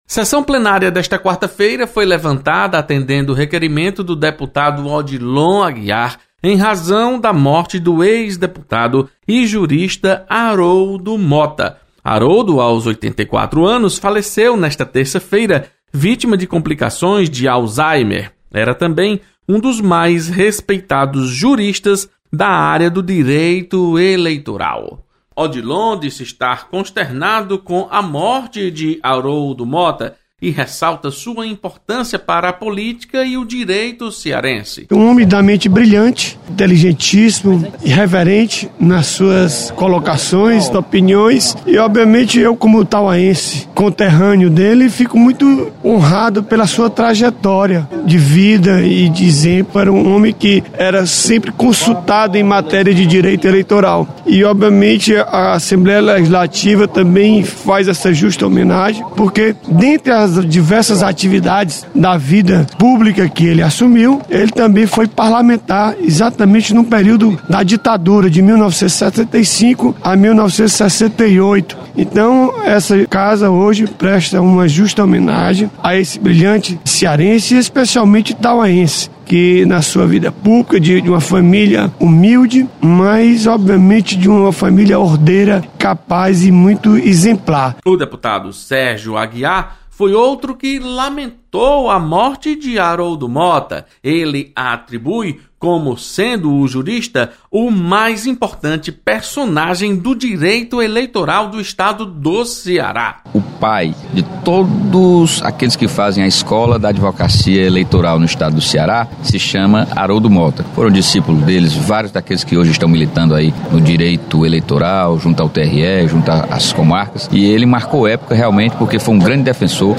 Deputados comentam sobre morte do ex-deputado Aroldo Mota.